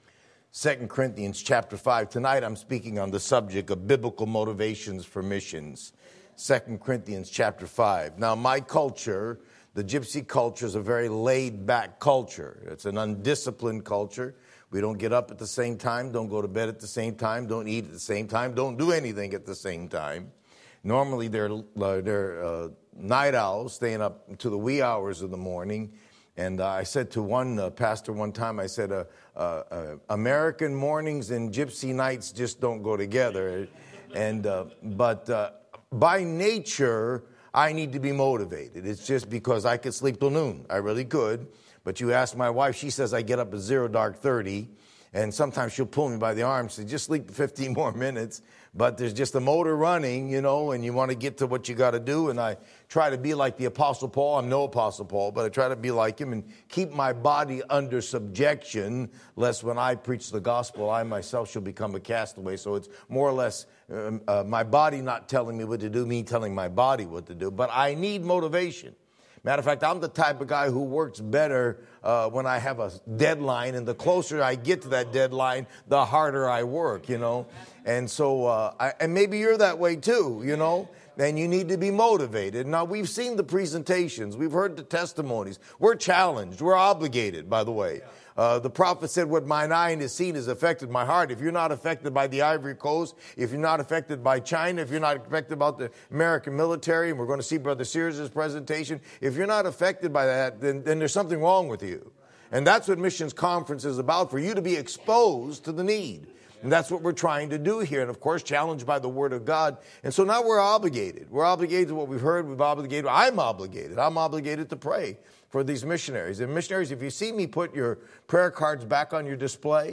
Sermon Audio :: First Baptist Church of Kingstowne